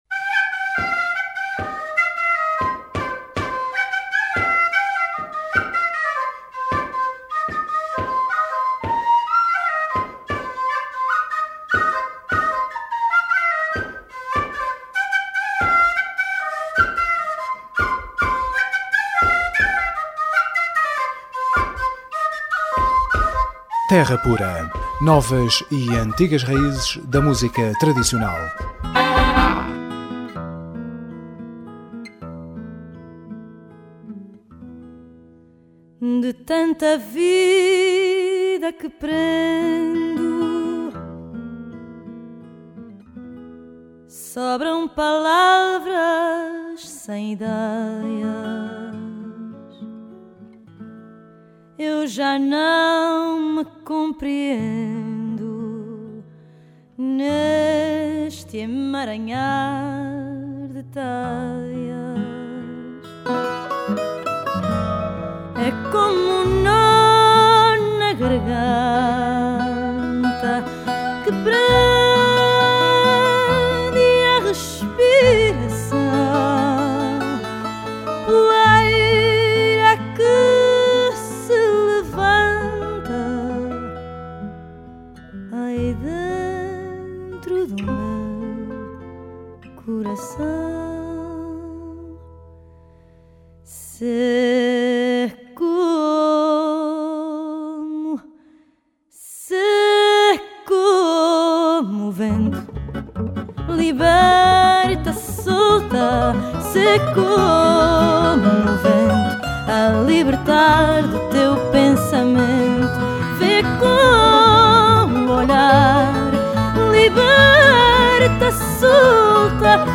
Terra Pura 5JUN13: Entrevista Cuca Roseta – Crónicas da Terra